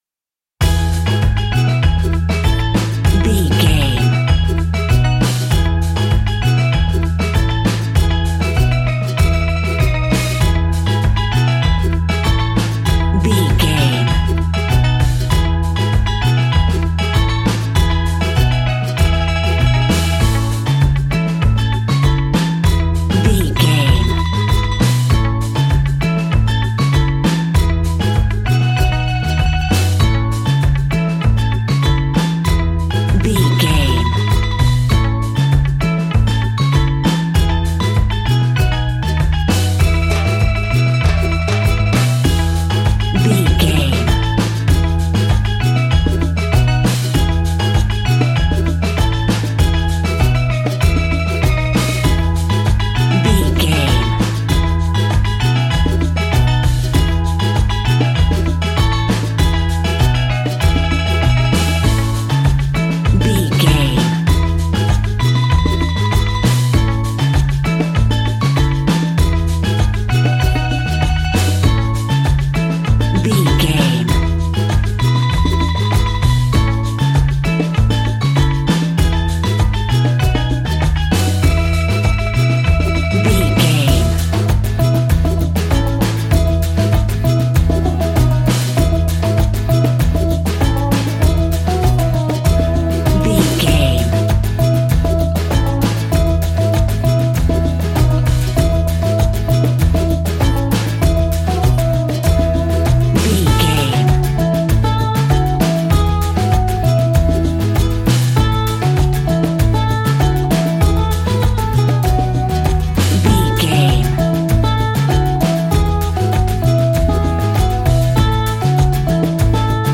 Ionian/Major
cheerful/happy
mellow
drums
electric guitar
percussion
horns
electric organ